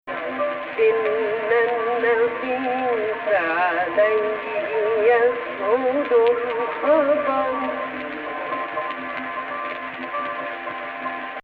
Not to speak of the obvious vocal power.